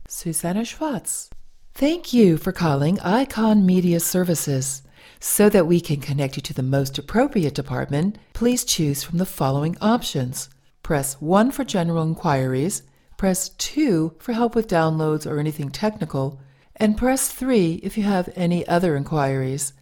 Sprechprobe: Sonstiges (Muttersprache):
An unorthodox voice artist/actor with a smoky, melodic voice, chocked-full of flexibility and instinctively versatile for portraying female + male meanies, arrogant snobs, charming - scathing villains and especially has a hearty repertoire of ordinary (and insane) characters (baby to senior) & accents from all-around.